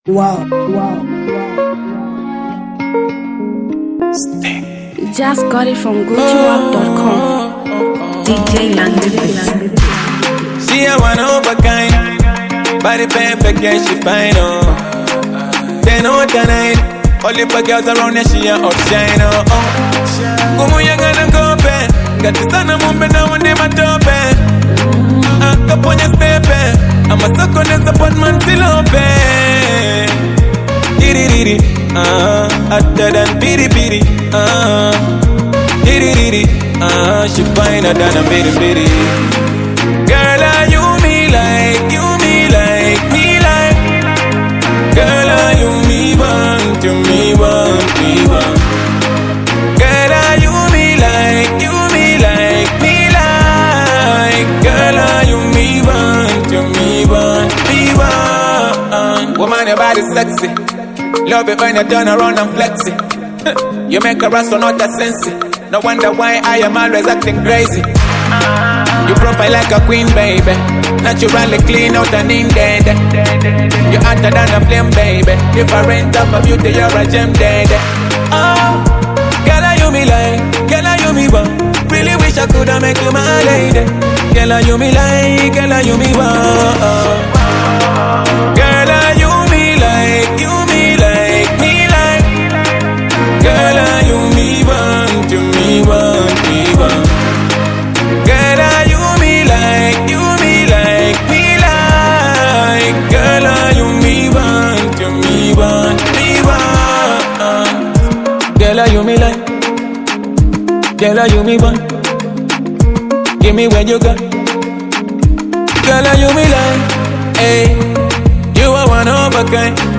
powerful melodic sound